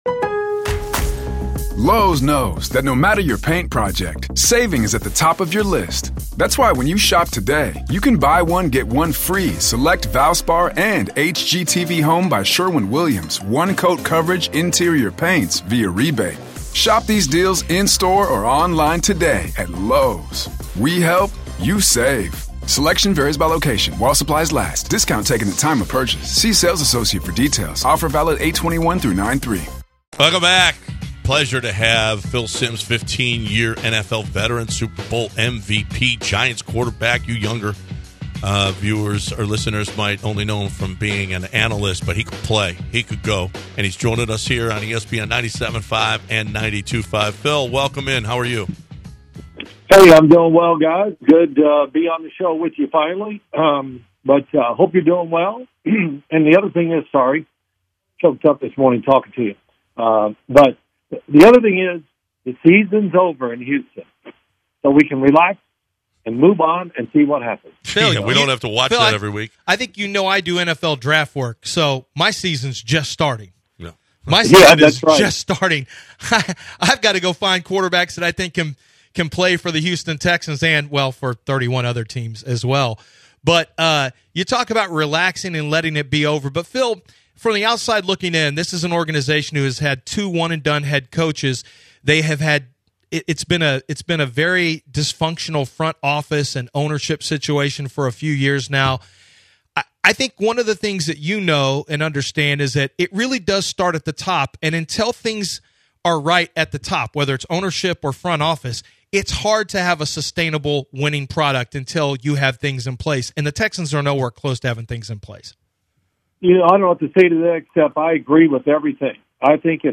Sportscaster Phil Simms joined The Bench
Phil Simms of the NFL on CBS joins The Bench to discuss the state of the Texans as well as preview the NFL Playoffs.